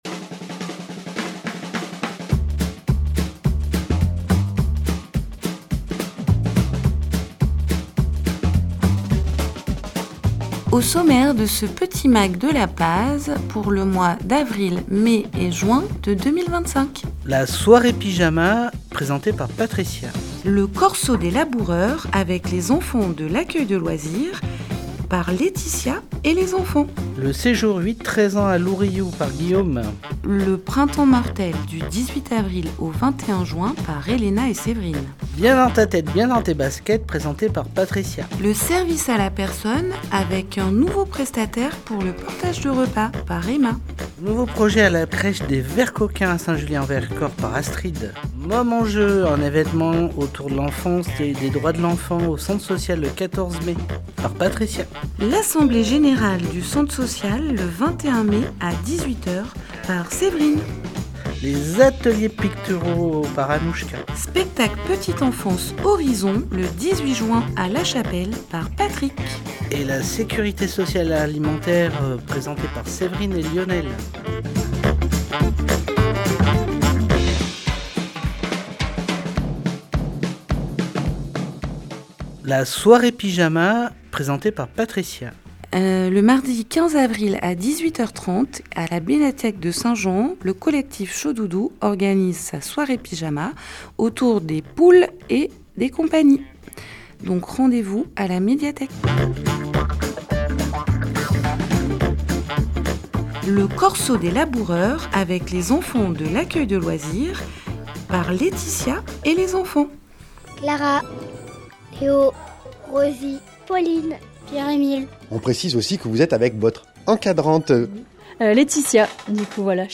Comme chaque trimestre les salarié.e.s du Centre social La Paz à St Jean en Royans présentent les actualités des différents services du centre social et les évènements phares à venir.